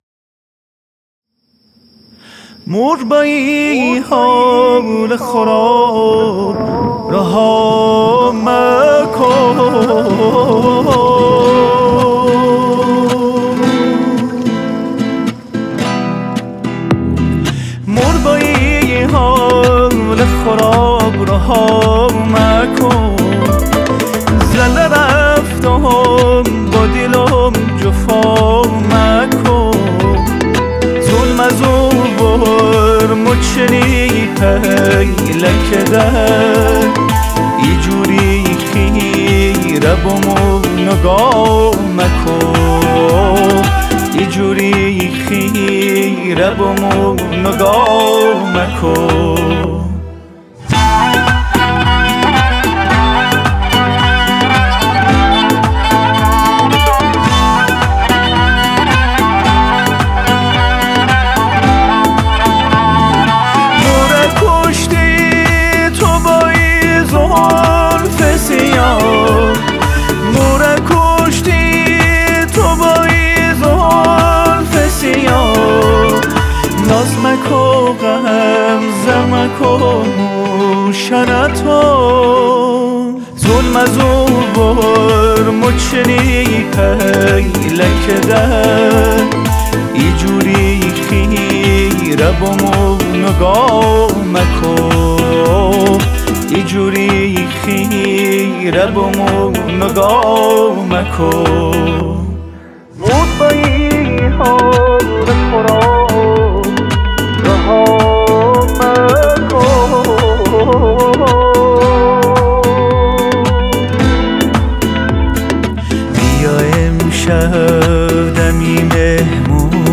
آهنگی با گویش نیشابوری